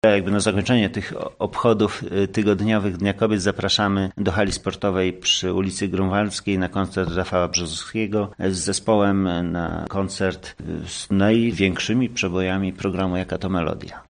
– zapowiadał na naszej antenie burmistrz Pajęczna Piotr Mielczarek.